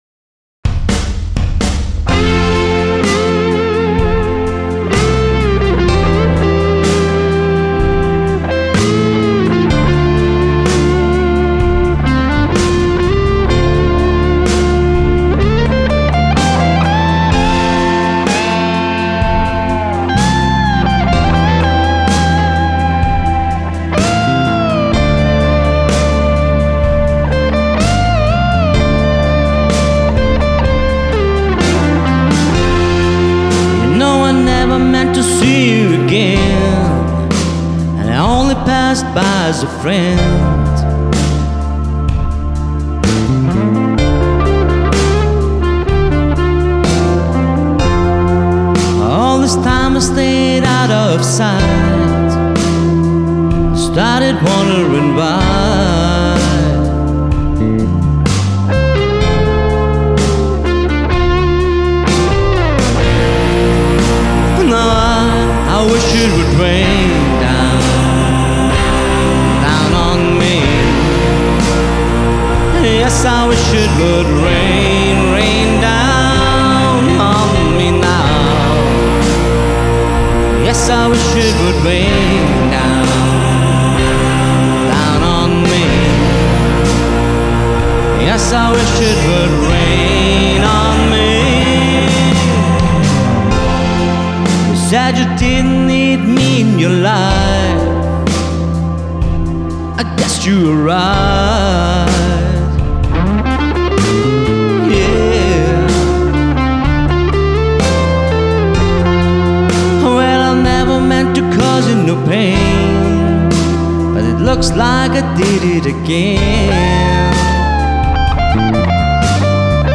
Coverversionen mit E-Gitarre, Gesang und Backing Track (BT).